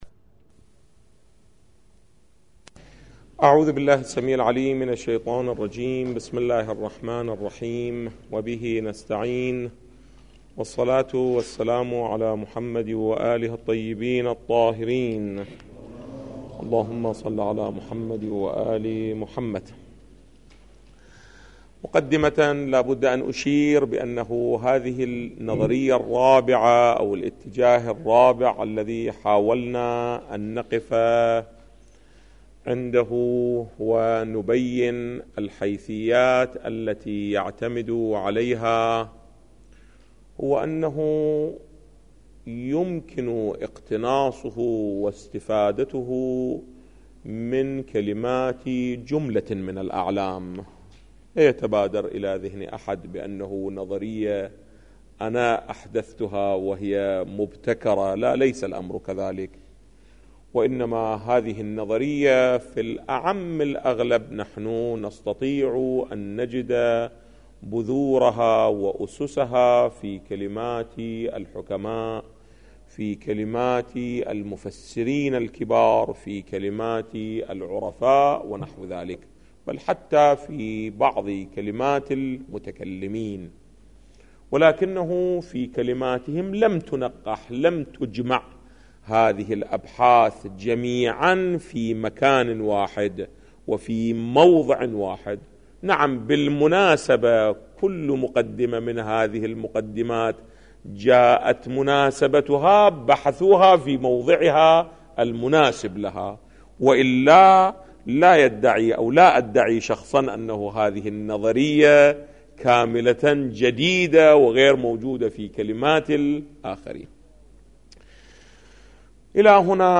استاد سيد کمال حيدري - قطع و ظن | مرجع دانلود دروس صوتی حوزه علمیه دفتر تبلیغات اسلامی قم- بیان